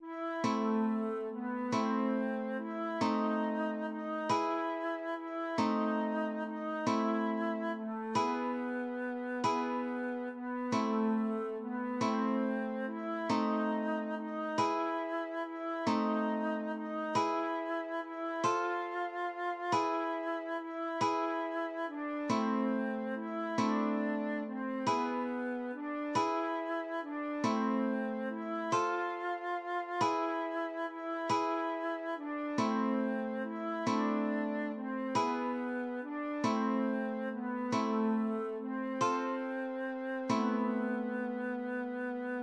acoustic guitar (nylon)